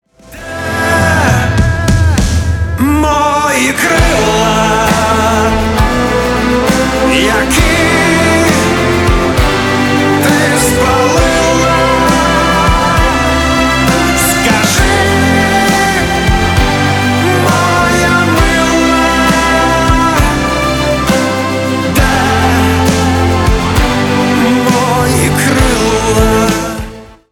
• Качество: 320, Stereo
мужской вокал
душевные
красивая мелодия
романтичные
украинский рок
поп-рок